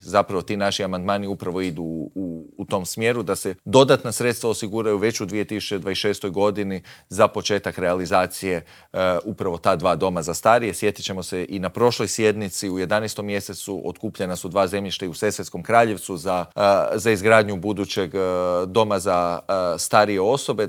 O ovim i drugim pitanjima smo u Intervjuu tjedna Media servisa razgovarali s predsjednikom zagrebačke Gradske skupštine, Matejem Mišićem.